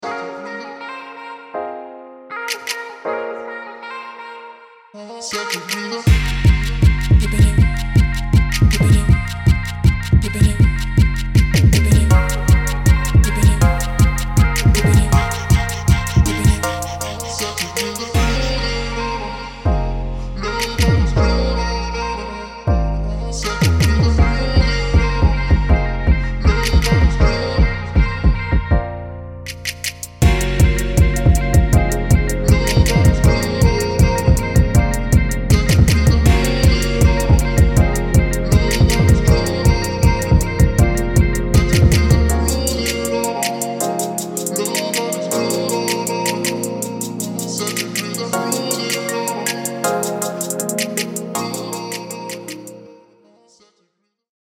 ジャージークラブのエネルギー
• ソウルフルでメロディックなひねりを加えた、ジャージークラブの影響力のあるサウンド
• 連打するキック、鮮やかなシンセ、豊かなボーカル、そしてオーガニックなメロディー